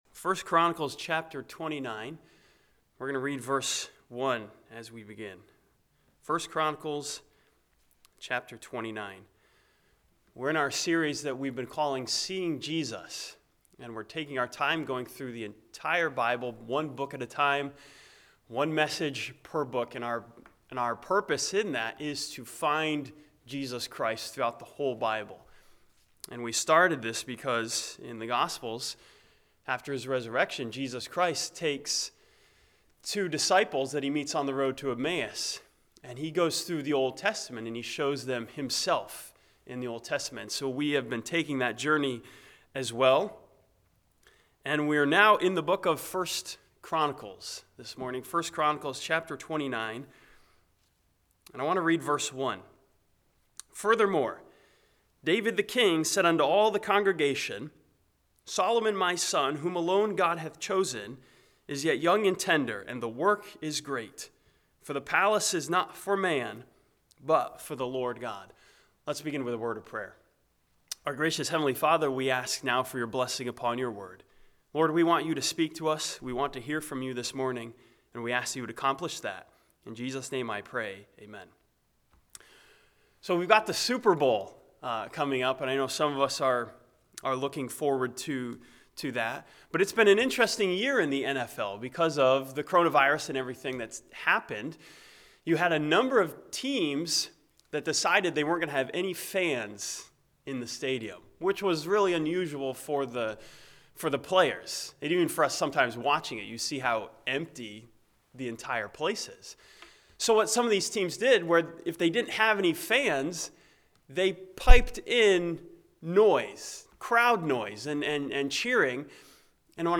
This sermon from 1 Chronicles chapter 29 studies King Solomon as a picture of Jesus Christ the successful Son.